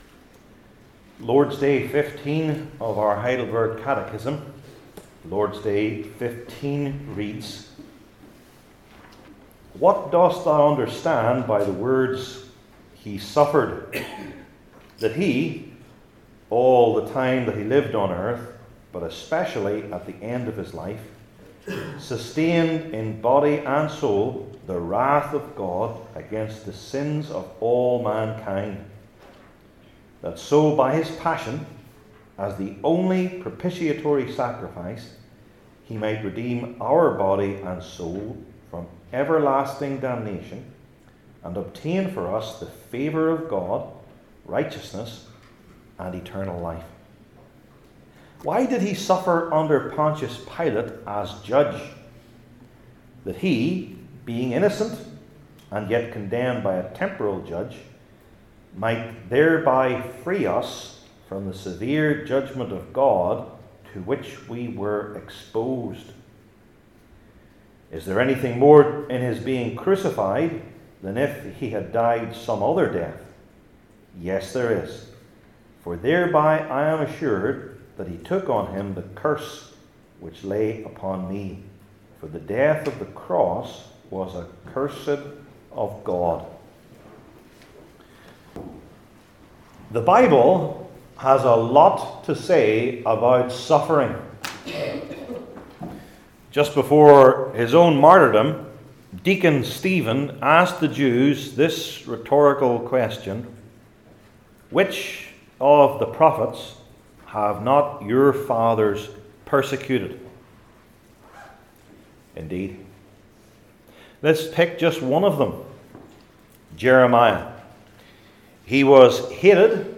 Heidelberg Catechism Sermons I. Terrible Sufferings II.